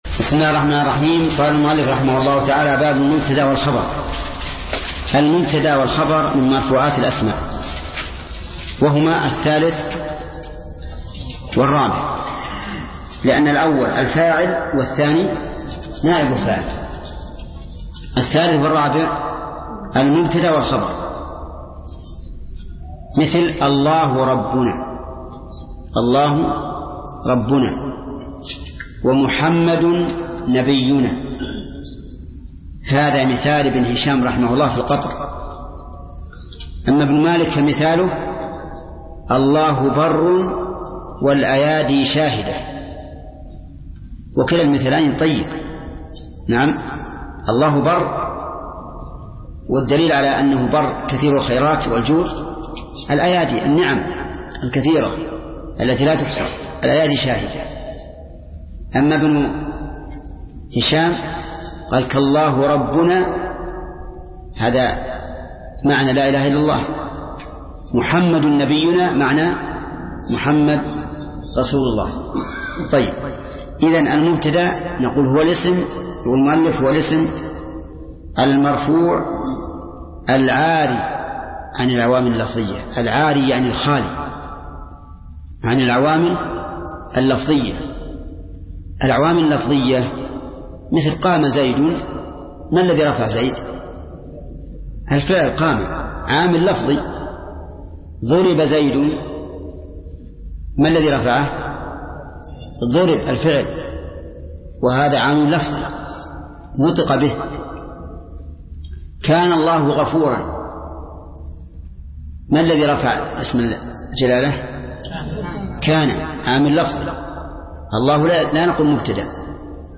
درس (12) : شرح الآجرومية : من صفحة: (245)، قوله: (المبتدأ والخبر)، إلى صفحة: (265)، قوله: (نواسخ المبتدأ والخبر)